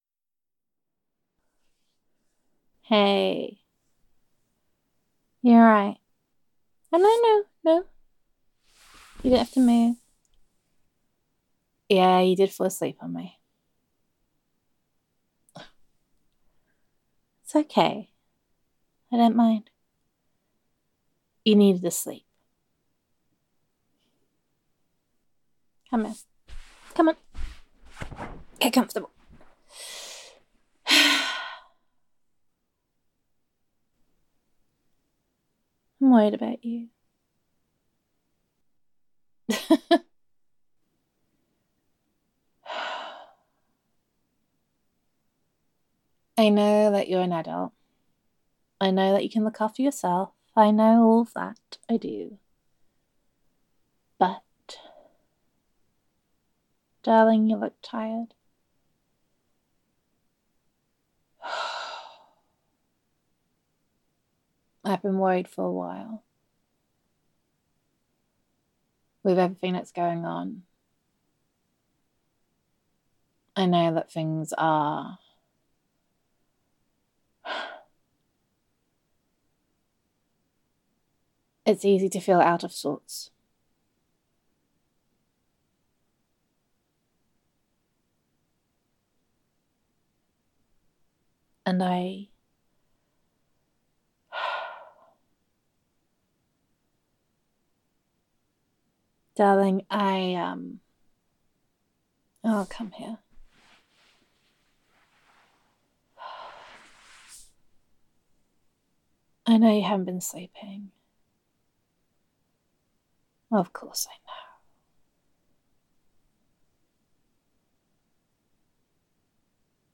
[F4A] Comfort for These Tough Times [Falling Asleep on Me][Concerned Girlfriend][Affectionate][You Work Too Hard][Closeness][Care and Concern][Self Care Is Important][You Need to Look After Yourself][Reassurance][Snuggling Up][Domestic Bliss][Gender Neutral][Concerned Girlfriend Roleplay]
Aww I definitely enjoy your comforting voice